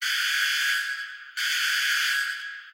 RadioAlarm.ogg